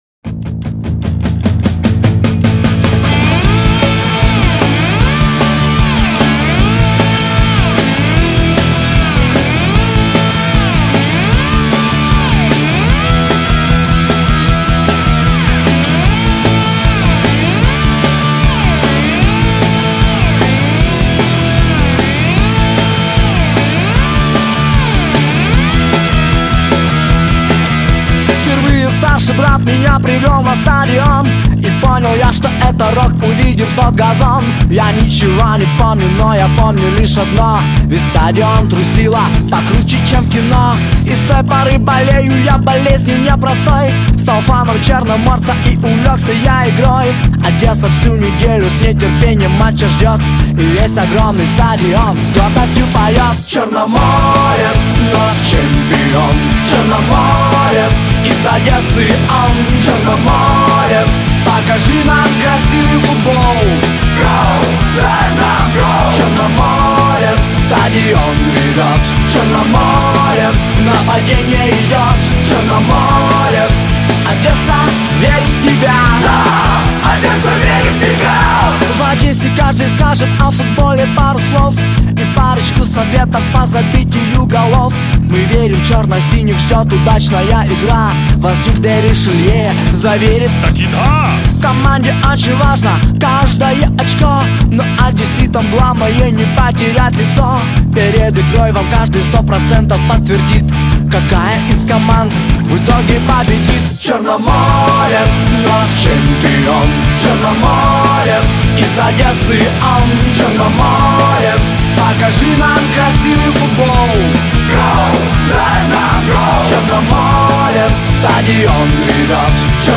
Теги: песня, гимн, черноморец